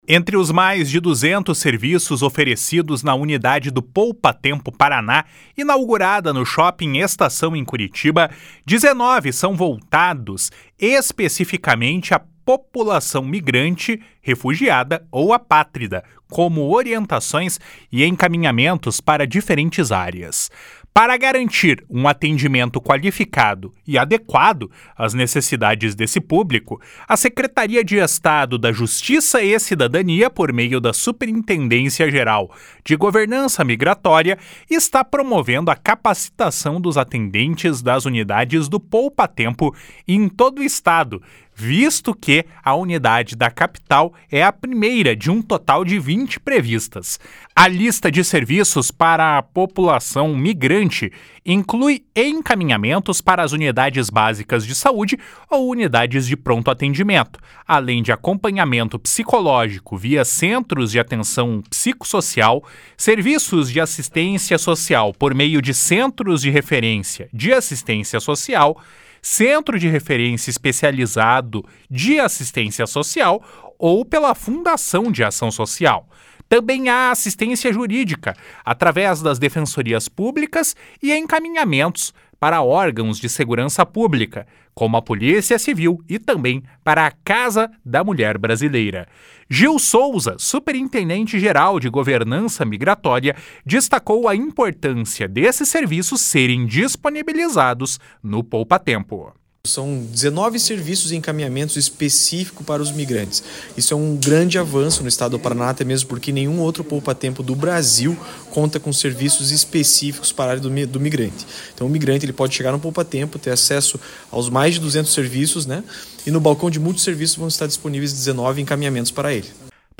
Gil Souza, superintendente-geral de Governança Migratória, destacou a importância desses serviços serem disponibilizados no Poupatempo. // SONORA GIL SOUZA //